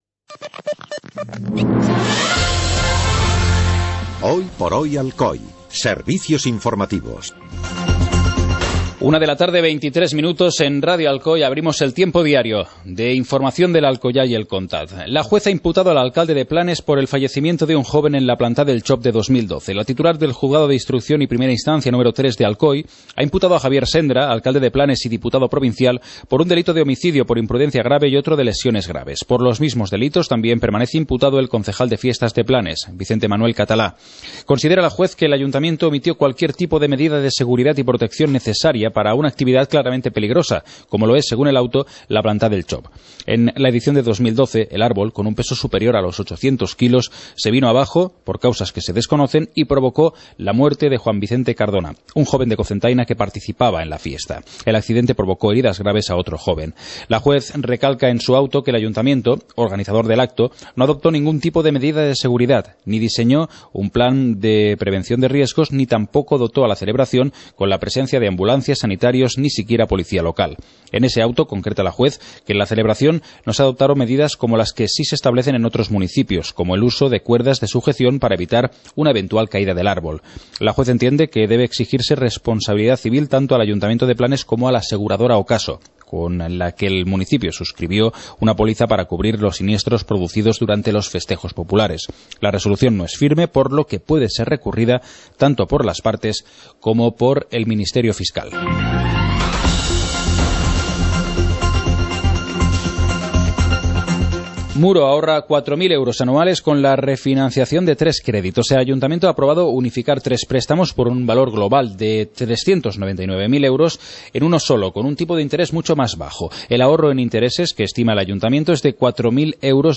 Informativo comarcal - lunes, 24 de noviembre de 2014